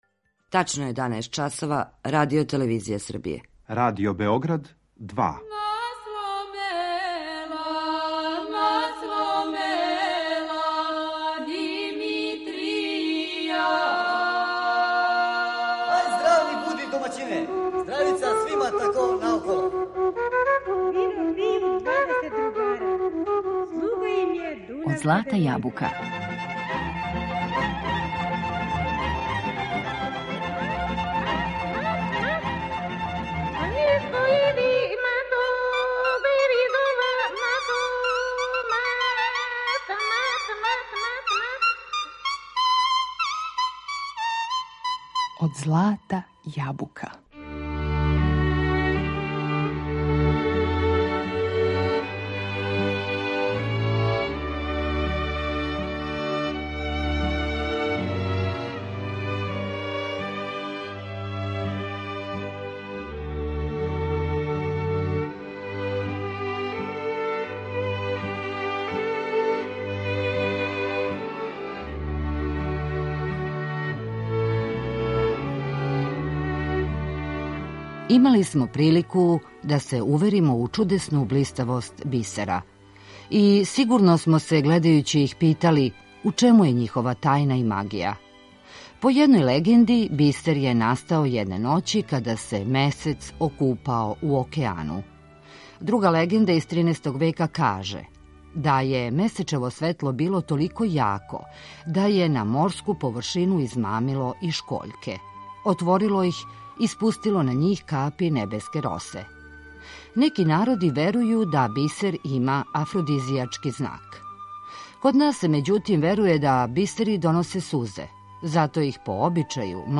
Данас у емисији Од злата јабука говоримо о Охриду и надалеко познатим охридским бисерима, уз проверене звуке традиционалне народне песме.